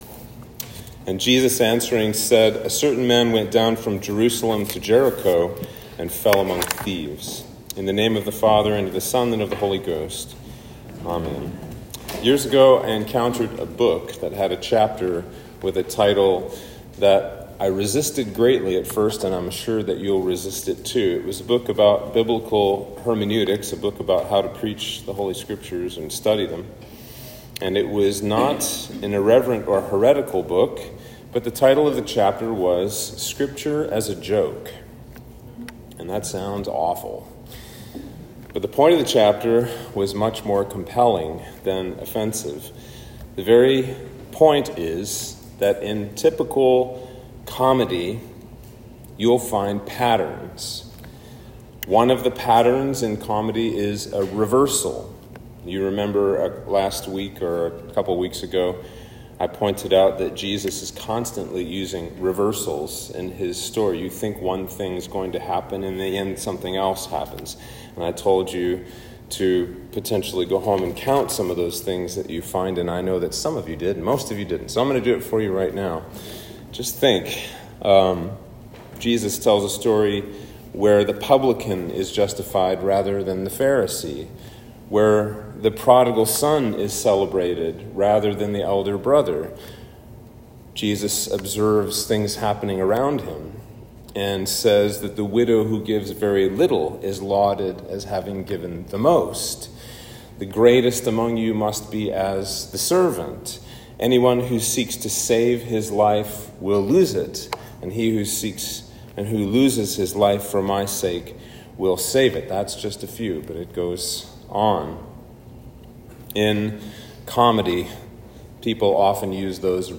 Sermon for Trinity 13